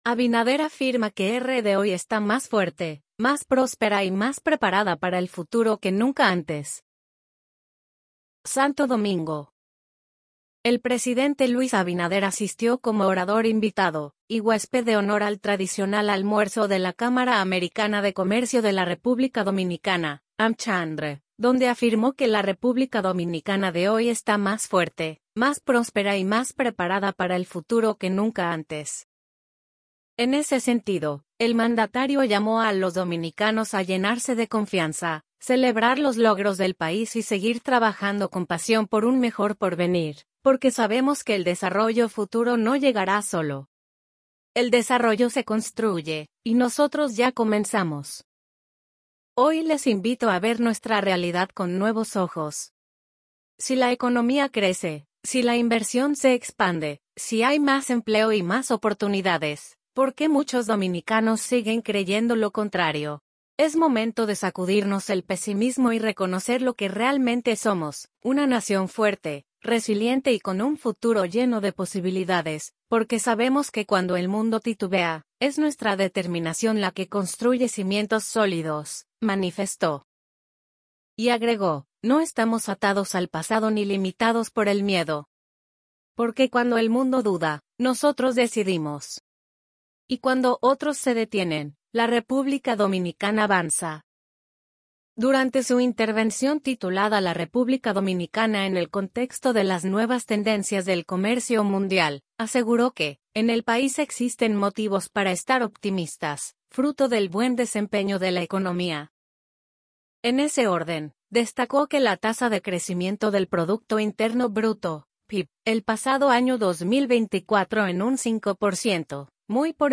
Santo Domingo. - El presidente Luis Abinader asistió como orador invitado y huésped de honor al tradicional almuerzo de la Cámara Americana de